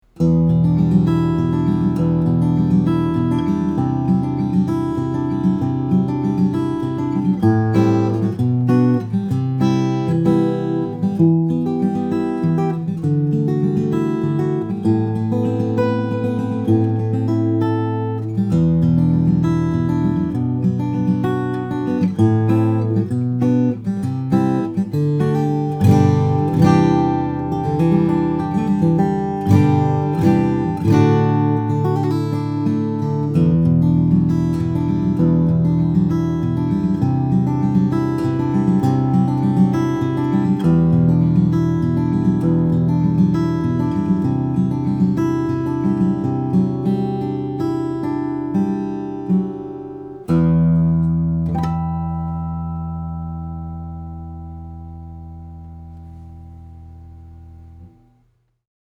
Here’s a classic workhorse guitar from Guild with a huge voice.